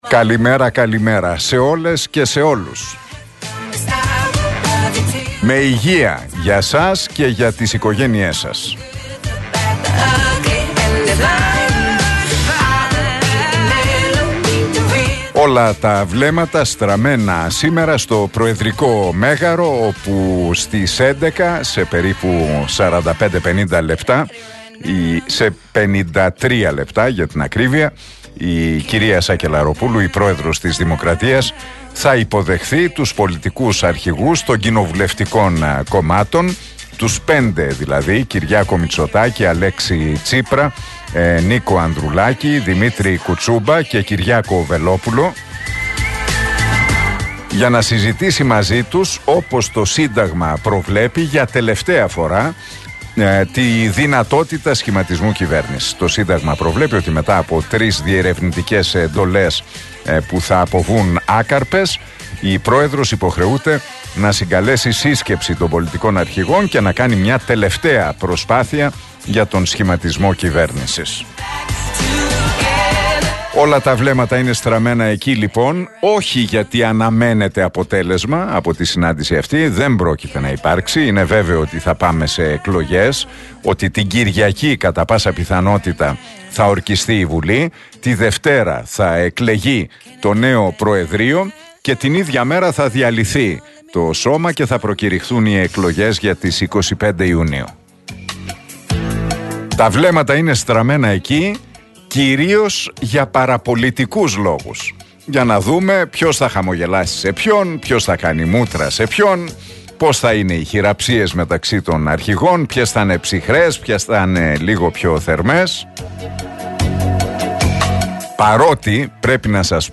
Ακούστε το σχόλιο του Νίκου Χατζηνικολάου στον RealFm 97,8, την Τετάρτη 24 Μαΐου 2023.